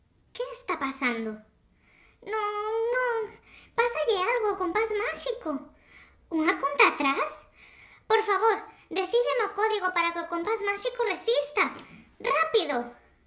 (Ruido de erro)